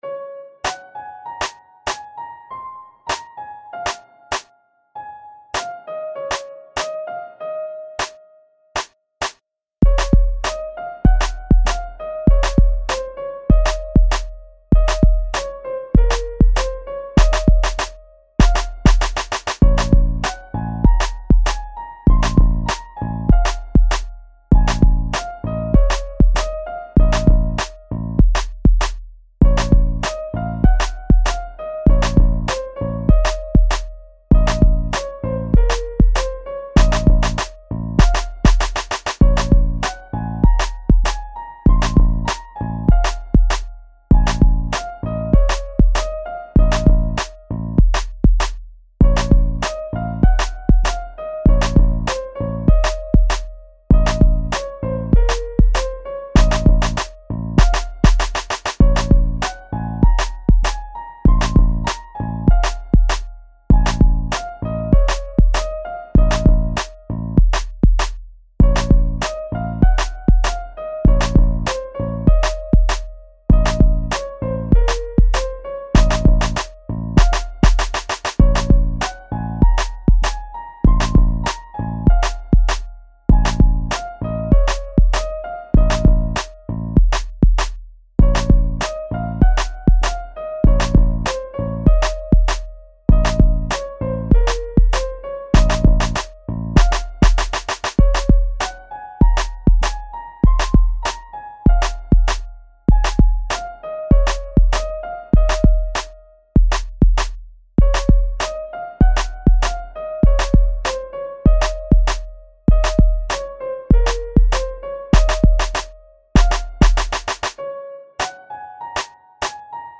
inst hiphop ピアノでメロを作ってループさせたモノ。
某ゲーム曲を思い出して作ってみたが展開が無いのが及第点。